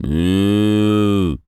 pgs/Assets/Audio/Animal_Impersonations/cow_moo_02.wav at master
cow_moo_02.wav